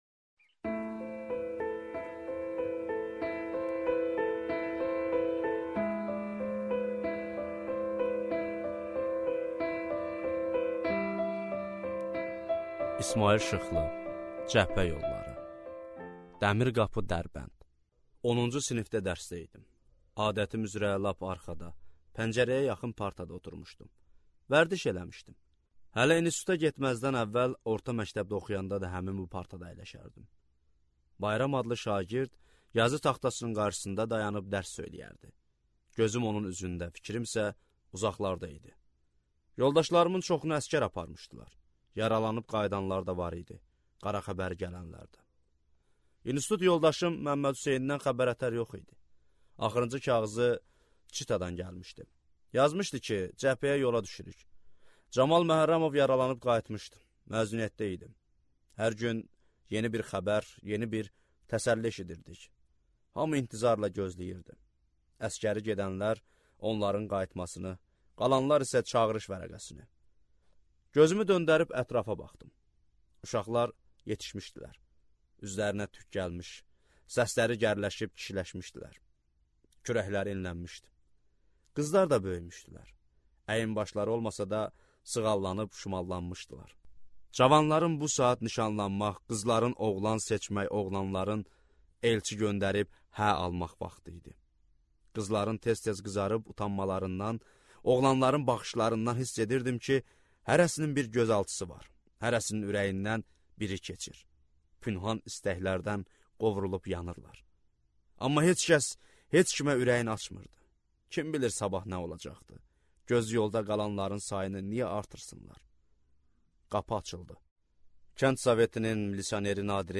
Аудиокнига Cəbhə yolları | Библиотека аудиокниг